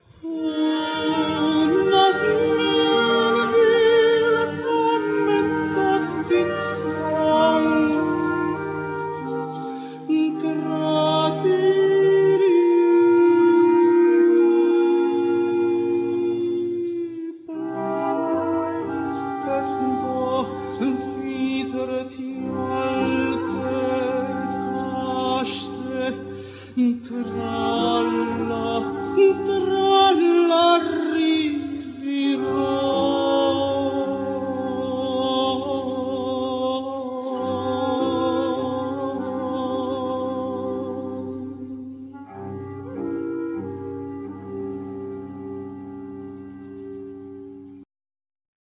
Tenor
Accordion
Flute
Clarinet
Percussion
Cello